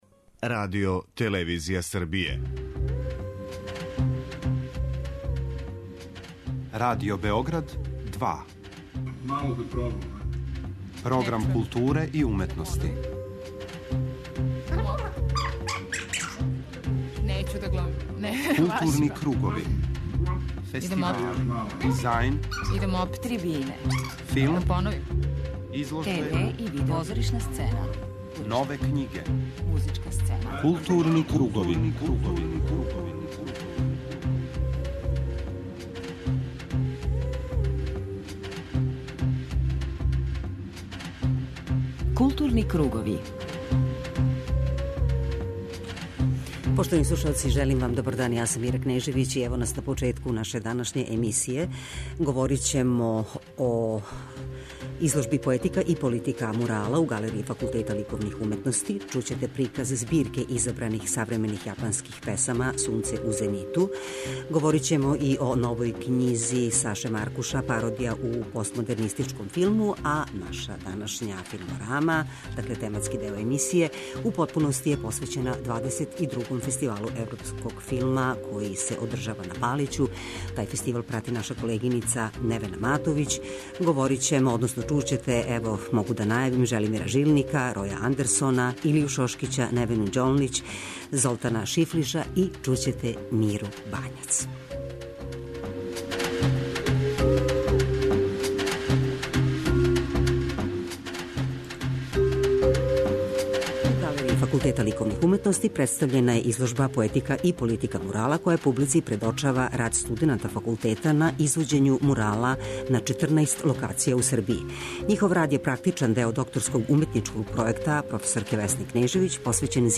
преузми : 53.18 MB Културни кругови Autor: Група аутора Централна културно-уметничка емисија Радио Београда 2.